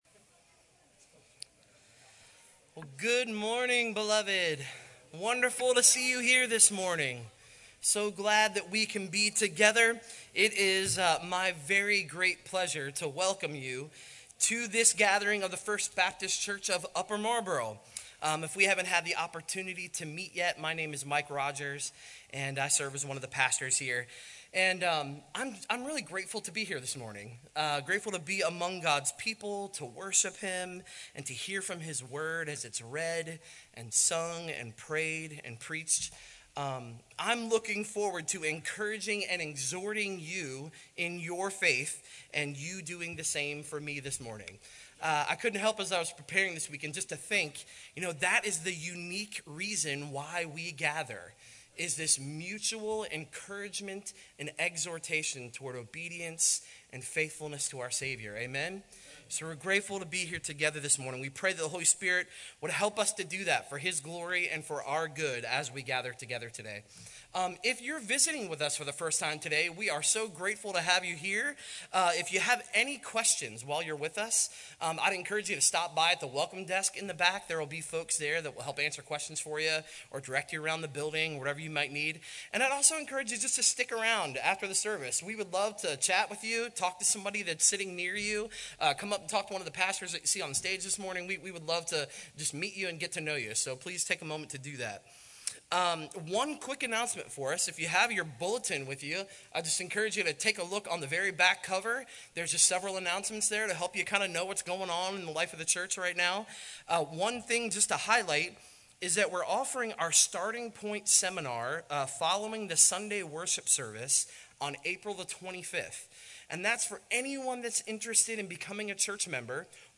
FBCUM-Live-Stream_41121.mp3